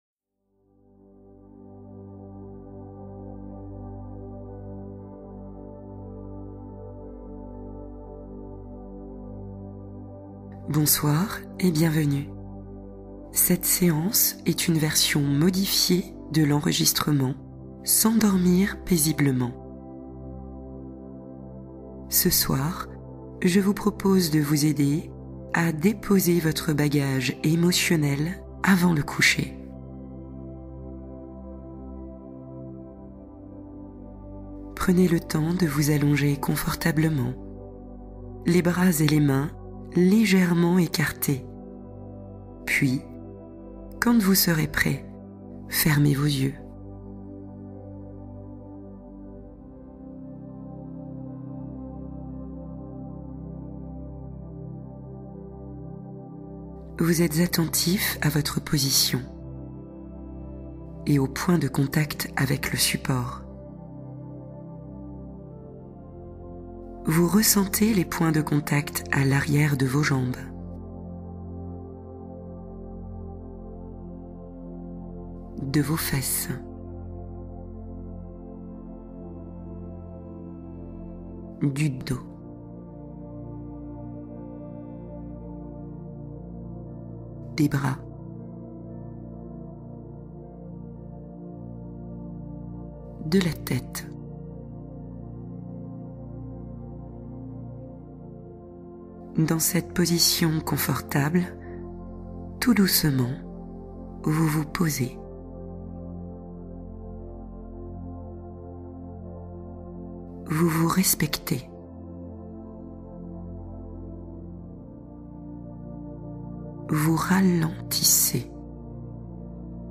Les mains du cœur : méditation guidée pour semer la paix intérieure